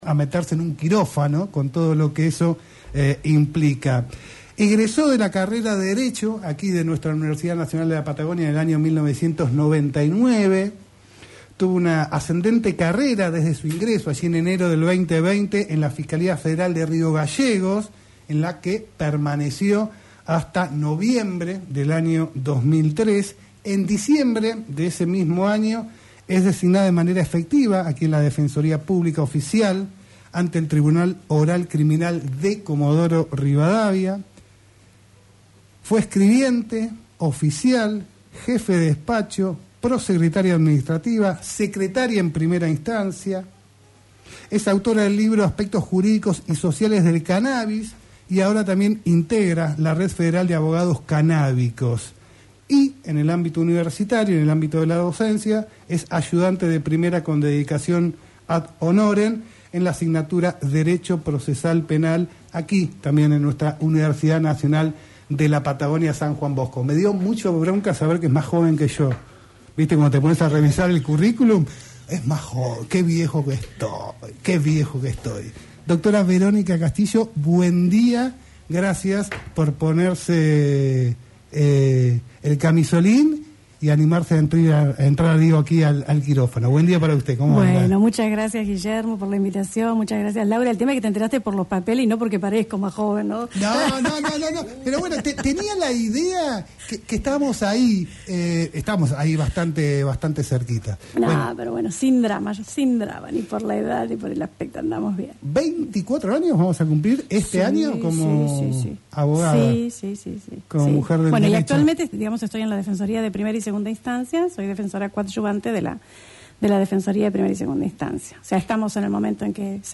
Verónica Castillo, abogada Diplomada en Cannabis para la Salud y Defensora Oficial en la Justicia Federal, habló en "El Quirófano" desde los estudios de LaCienPuntoUno, sobre cómo se tratan en la Justicia los casos por cannabis, las políticas del gobierno de Javier Milei con el REPROCANN y el caso de Estados Unidos sobre el pedido de bajar a la marihuana a una droga de bajo riesgo.